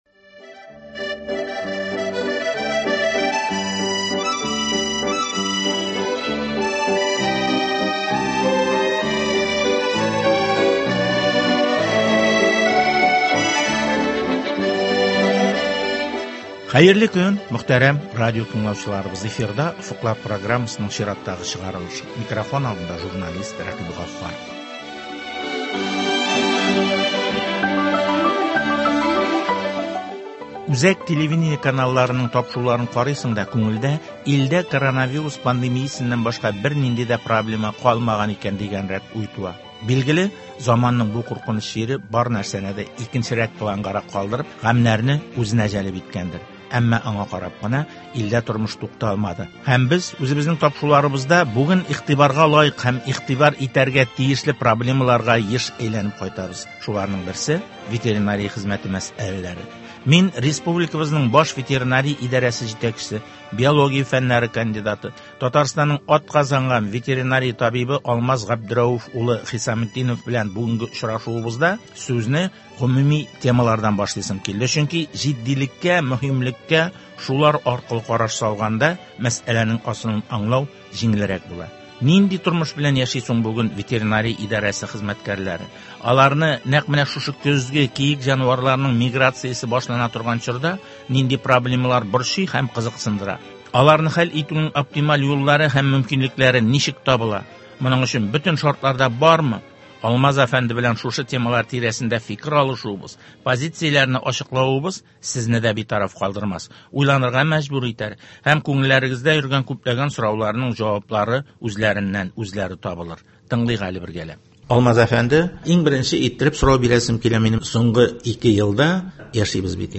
Республикабызның баш ветеринария идарәсе җитәкчесе, биология фәннәре кандидаты, Татарстанның атказанган ветеринария табибы Алмаз Хисаметдинов белән республикабызда ветеринария хезмәте мәсьәләләренең бүгенге торышы, күңелне борчыган һәм кичектергесез хәл ителергә тиешле мәсьәләләр хакында әңгәмә.